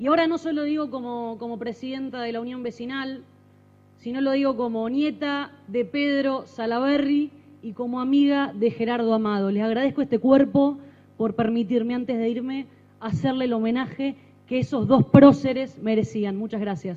A 40 años de la recuperación democrática, cuando el acto parecía terminado, Sallaberry tomó el micrófono y destacó sobre las demás a estas personas, cuyas responsabilidades en ese oscuro período habían sido cuidadosamente omitidas de las semblanzas leídas previamente.